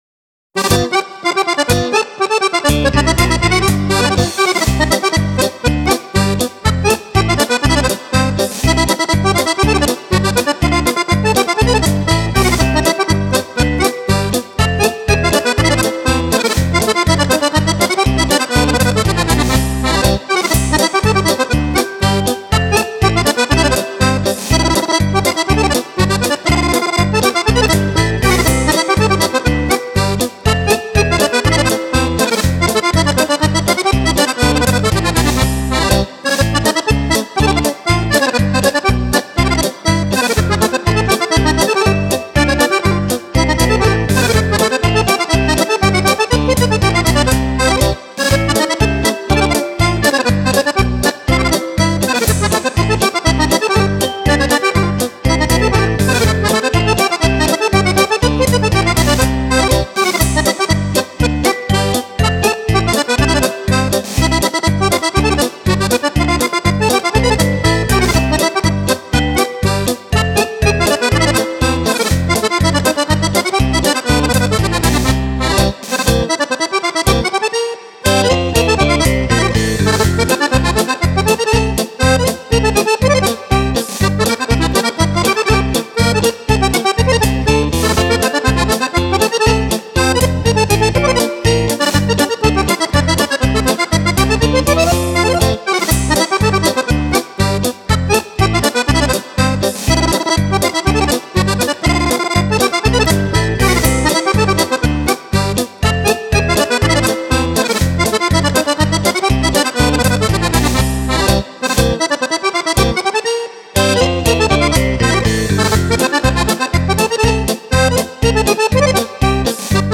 Polka
10 ballabili per Fisarmonica
Fisarmonica
Chitarre